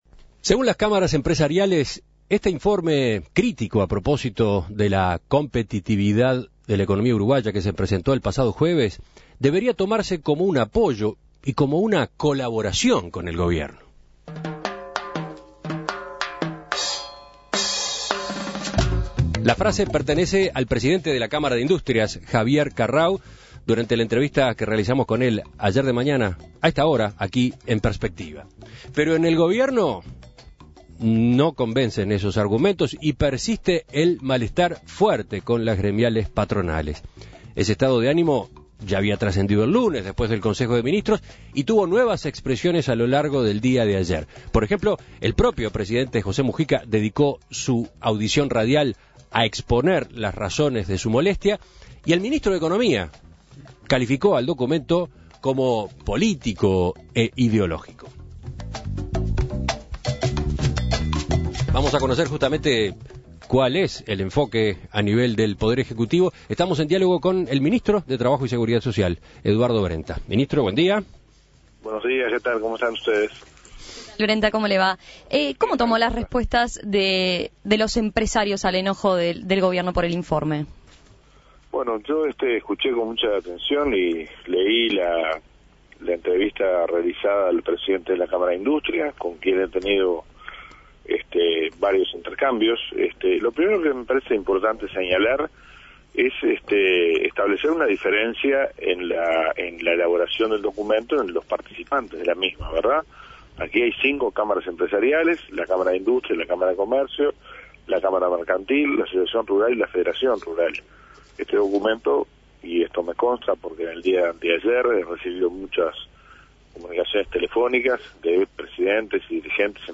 Escuche la entrevista a Eduardo Brenta